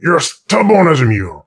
woodboxdestroyed01.ogg